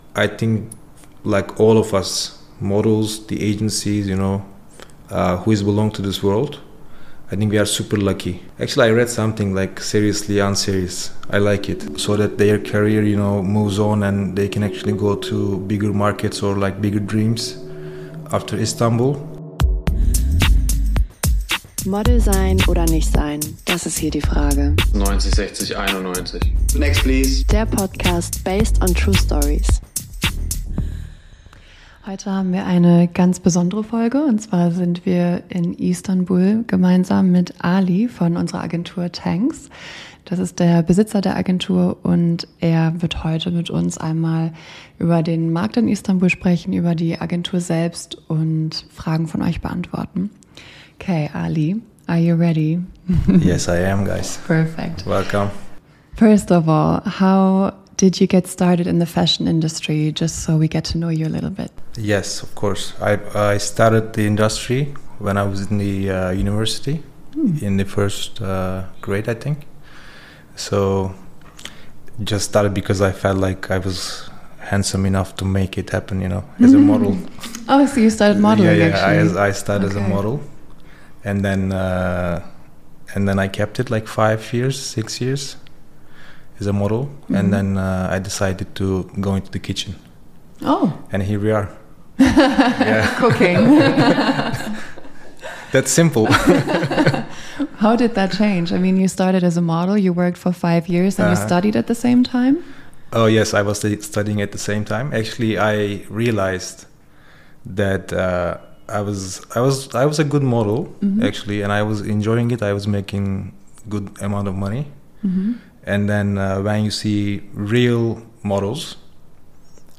Beschreibung vor 3 Monaten This episode is a milestone for us - our very first episode in English.